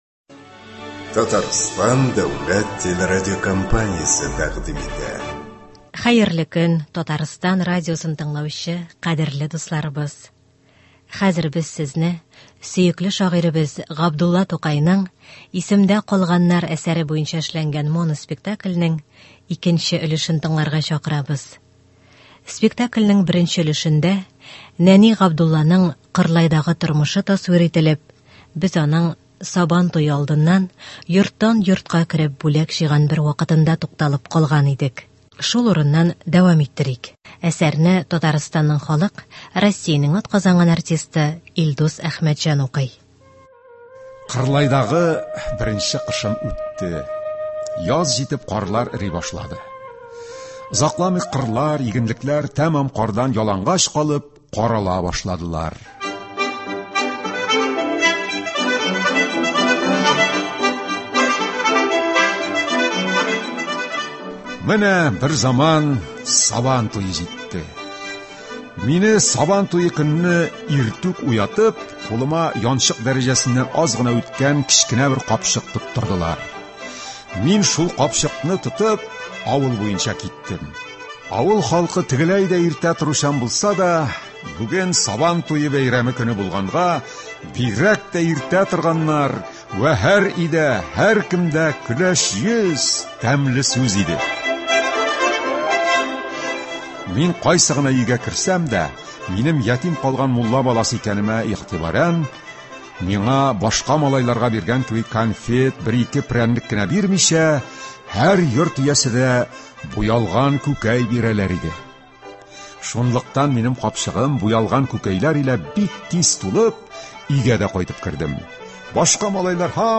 “Исемдә калганнар”. Моноспектакль.